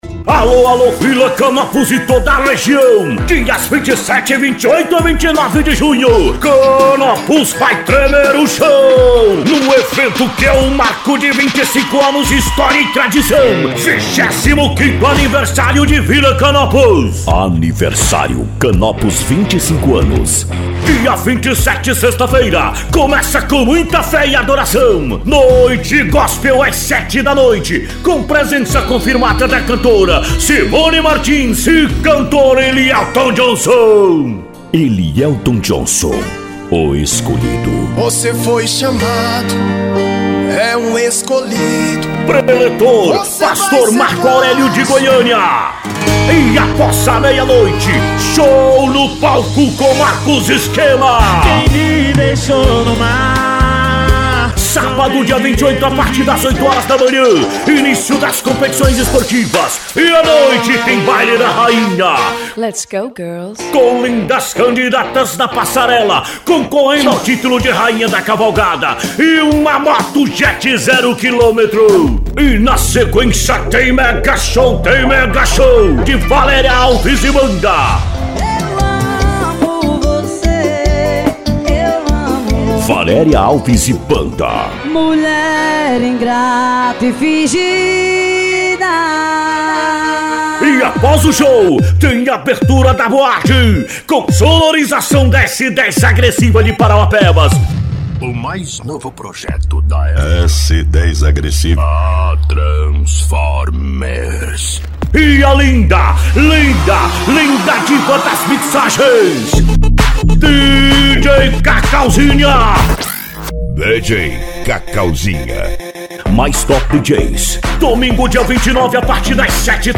ESTILO RODEIO AUDIO GUIA